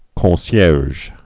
(kôɴ-syârzh)